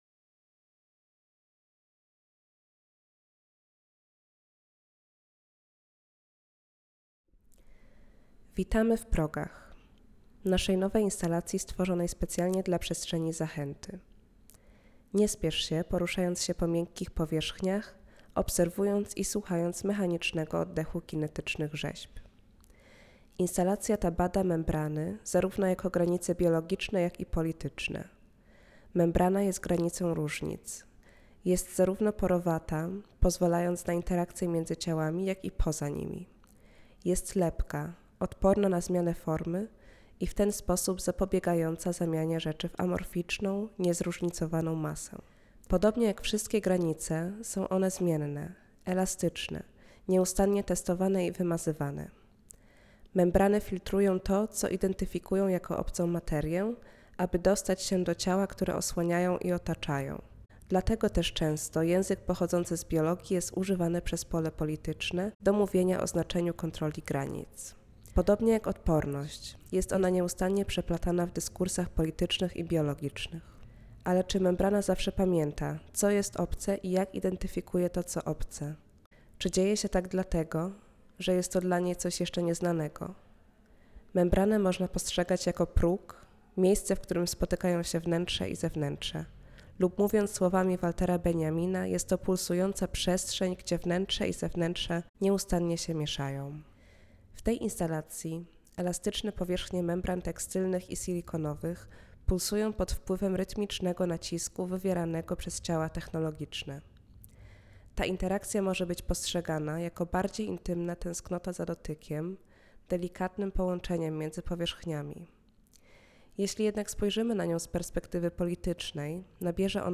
Audioprzewodnik do wystawy Pakui Hardware. Progi / Thresholds